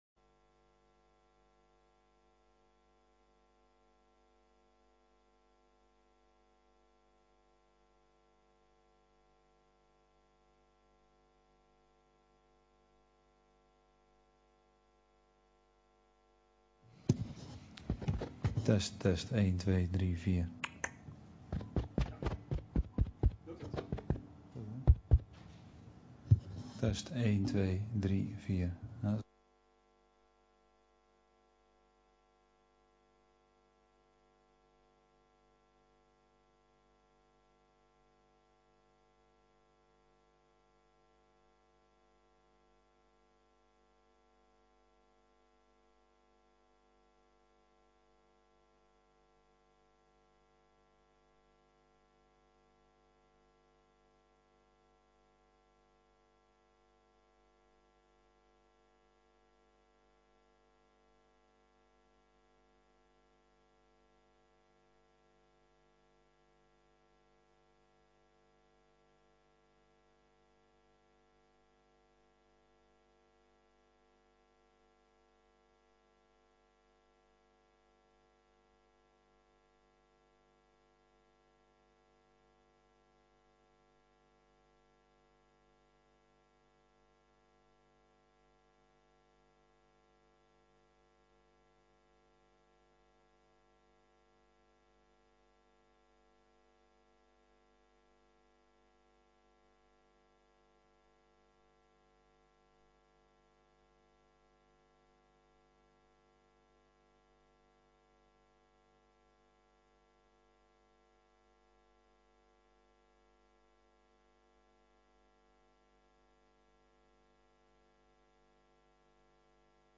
Raadsplein met raadsvergadering en raadssessies